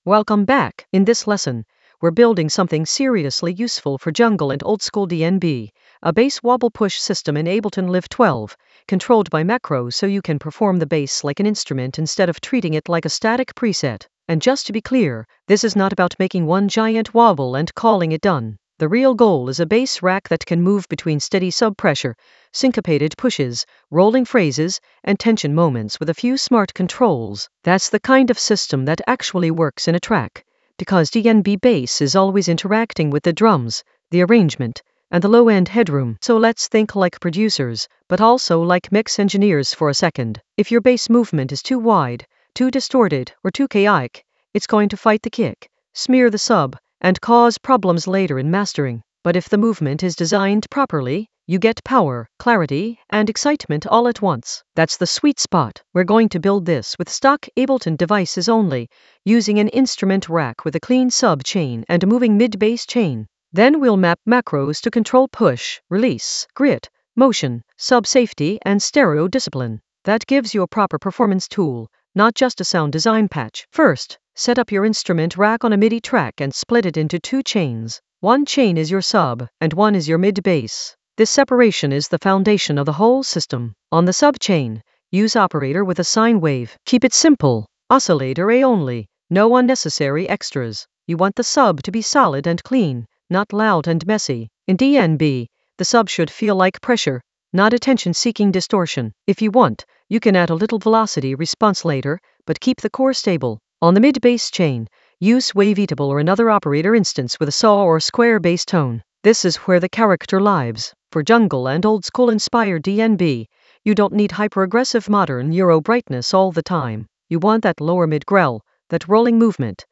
Narrated lesson audio
The voice track includes the tutorial plus extra teacher commentary.
An AI-generated advanced Ableton lesson focused on Bass wobble push system using macro controls creatively in Ableton Live 12 for jungle oldskool DnB vibes in the Mastering area of drum and bass production.